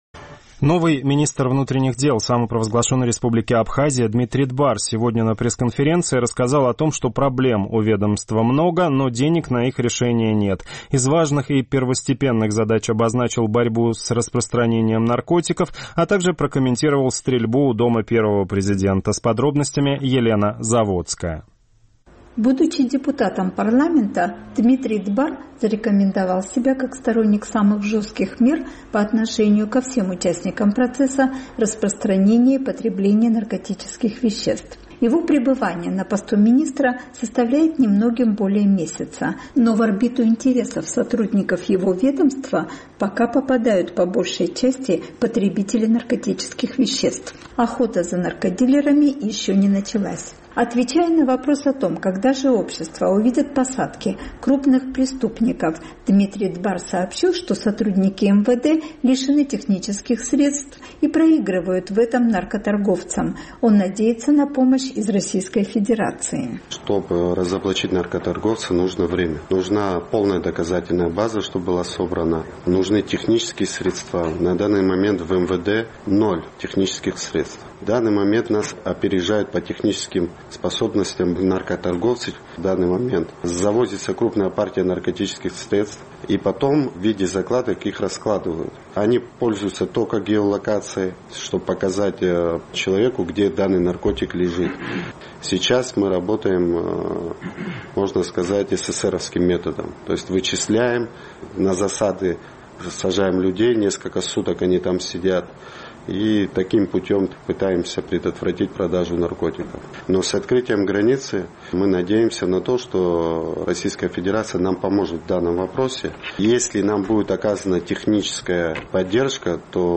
Министр внутренних дел Дмитрий Дбар рассказал на пресс-конференции о том, что проблем у МВД много, но денег на их решение нет.